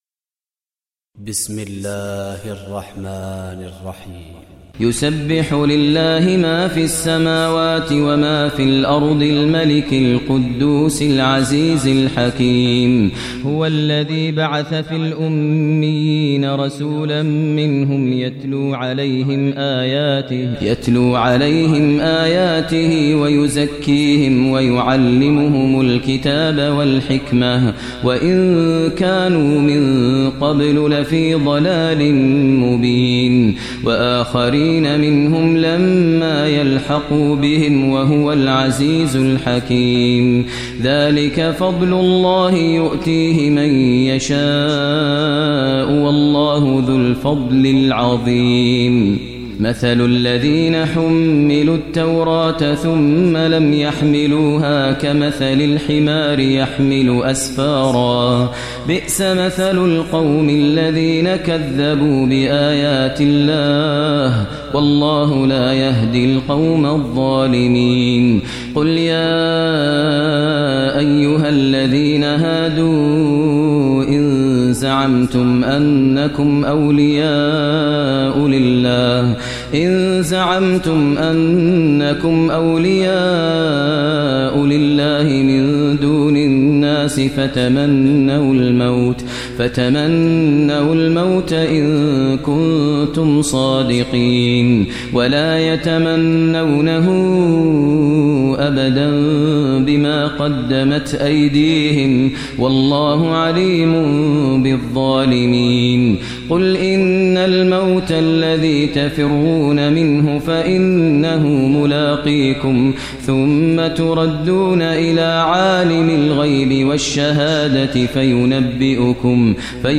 Surah Jummah Recitation by Sheikh Maher Mueaqly
Surah Jummah, listen online mp3 tilawat / recitation in Arabic recited by Imam e Kaaba Sheikh Maher al Mueaqly.